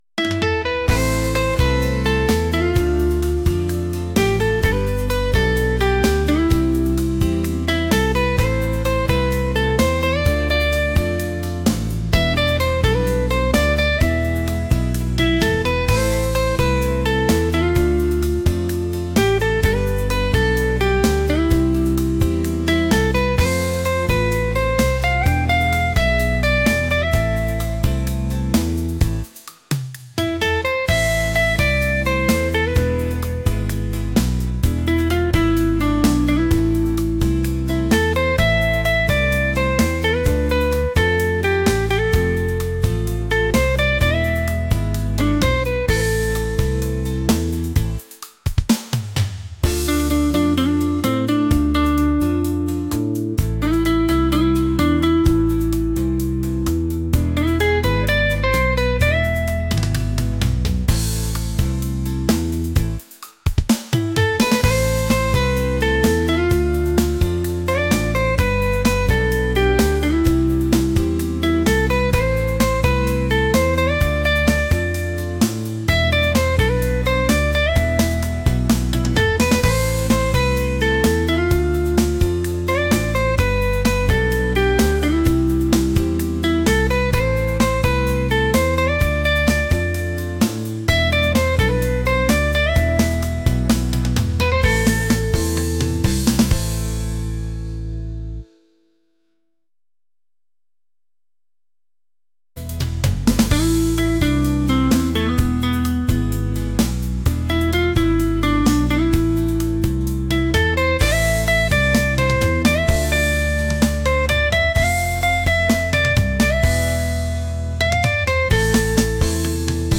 groovy | upbeat | pop